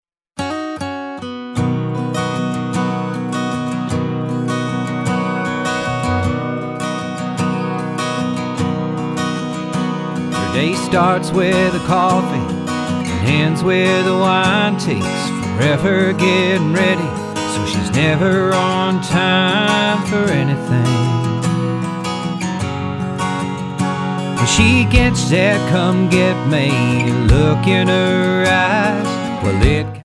Tonart:B Multifile (kein Sofortdownload.
Die besten Playbacks Instrumentals und Karaoke Versionen .